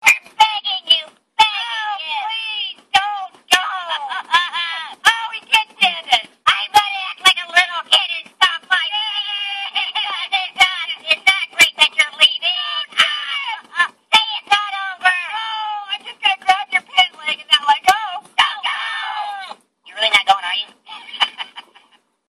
Promise To Act Grown-Up is a hoops&yoyo don't leave greeting card with sound.
Card sound